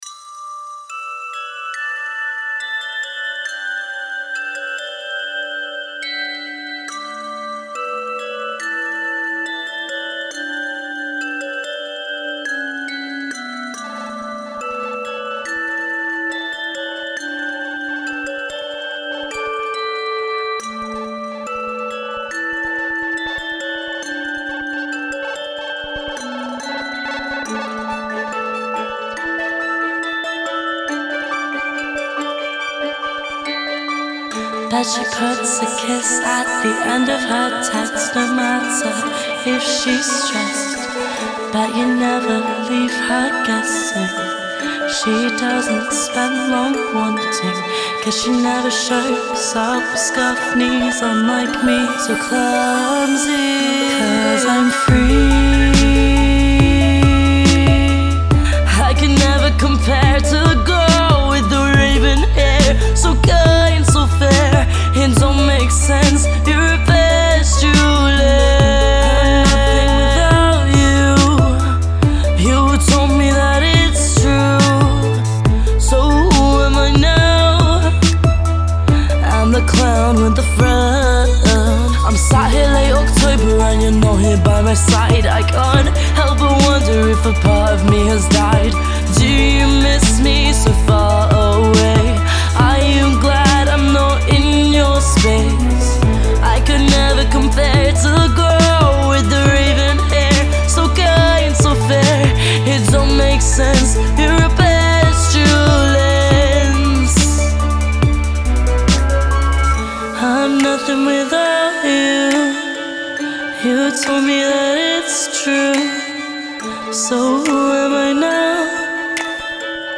With its ethereal ‘other-worldly’ intro